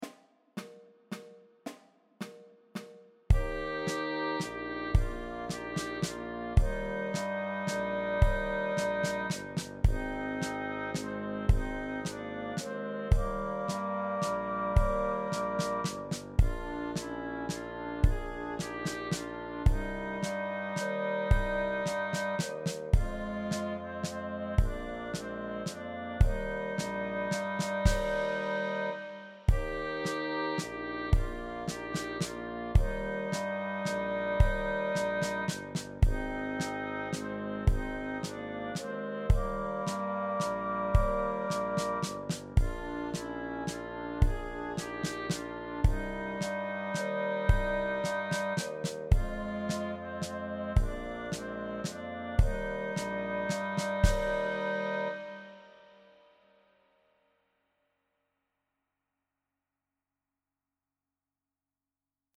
• 16 sehr leichte, dreistimmige Weihnachtslieder